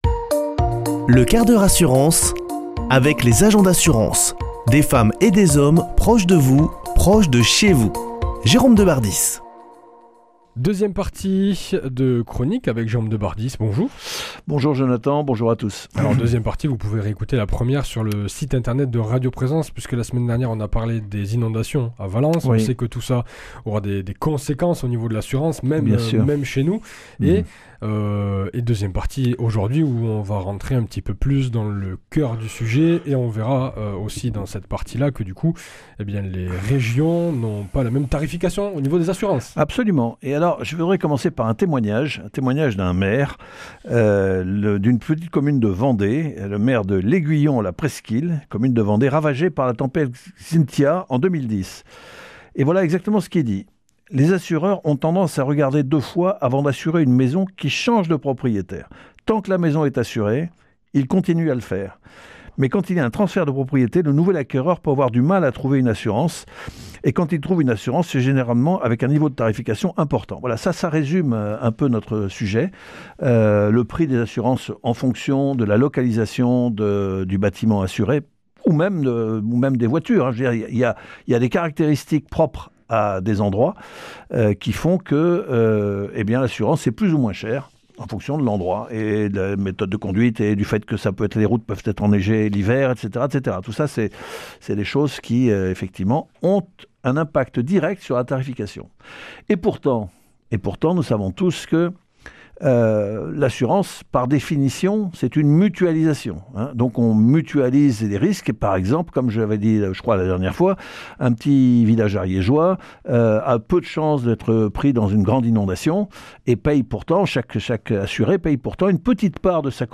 Une émission présentée par
Chroniqueur